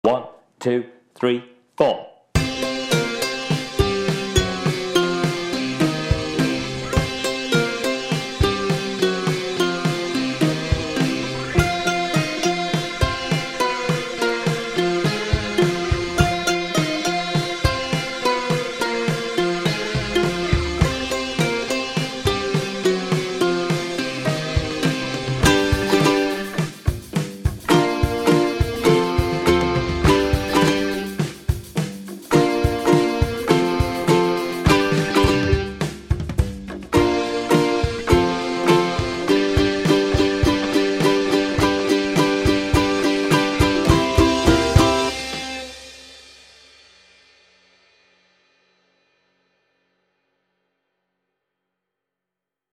Backing Track
It's not actually that fast.